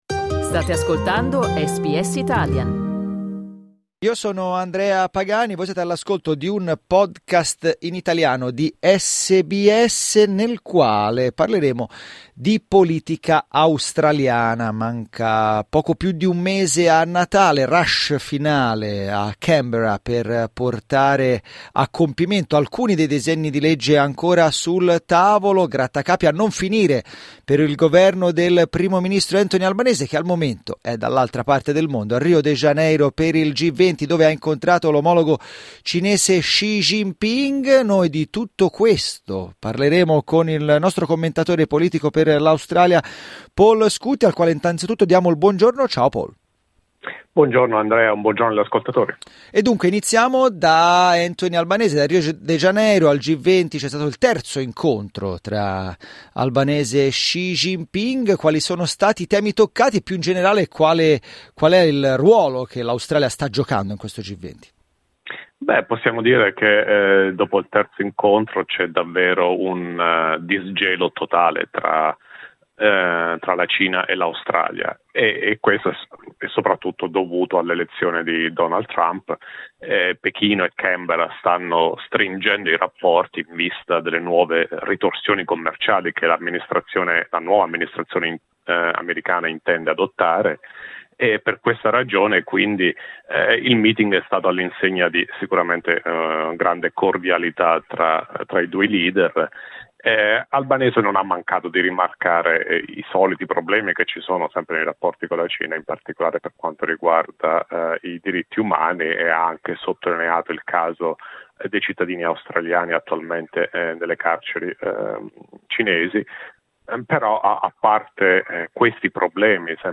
Albanese è a Rio de Janeiro e celebra la distensione dei rapporti con Pechino, ma a Canberra il governo incassa il retromarcia dell'opposizione sul disegno di legge che prevede il tetto agli studenti stranieri. L'analisi del commentatore politico